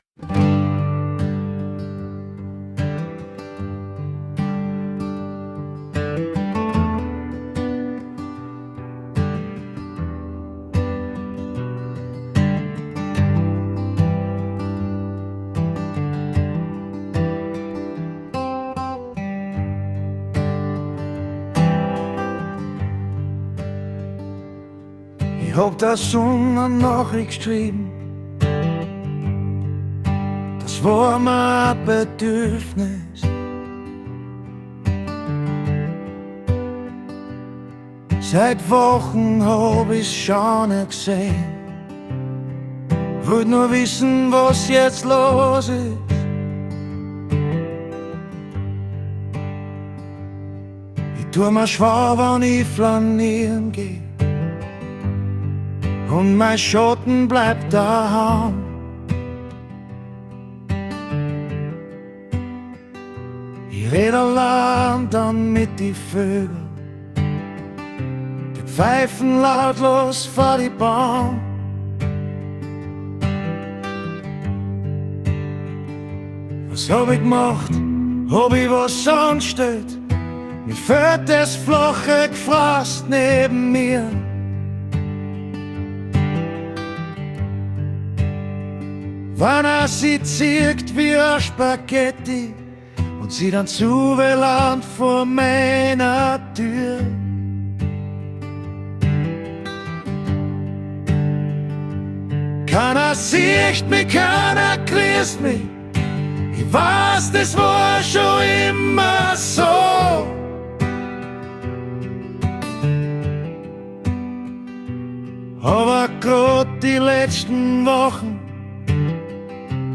Hab heute den bisher nicht vertonten Text von mir, der auch ins Booklet kommt, einem Musik KI Programm zum Spielen übergeben.
Das Ergebnis, nachdem ich einzig den Stil "Akustik Americana" und "male Singer" selektiert habe, nun hier …